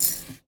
R - Foley 54.wav